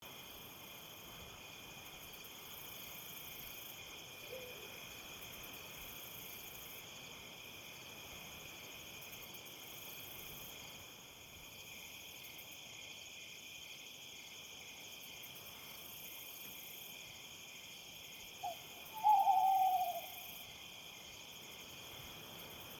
forestnight.mp3